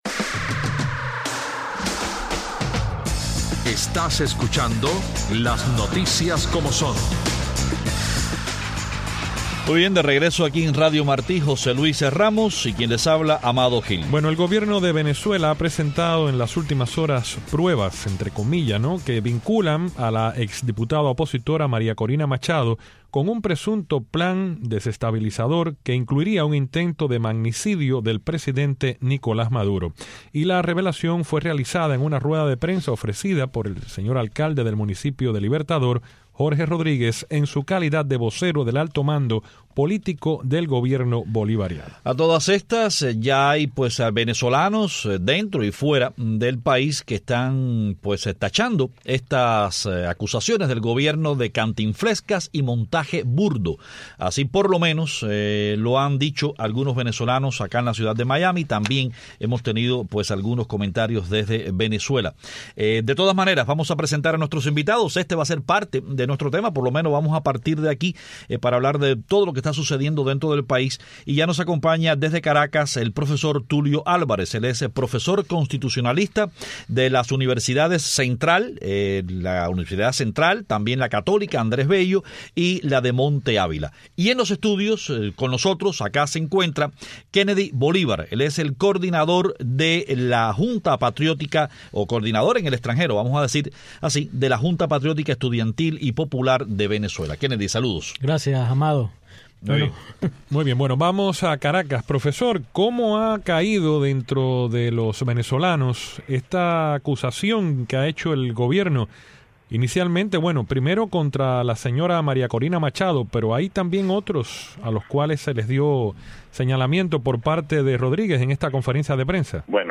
Mientras tanto, en Washington, la Cámara de Representantes aprobó una ley para imponer sanciones económicas a venezolanos responsables de violar los derechos humanos de los manifestantes. Tenemos en el estudio al joven venezolano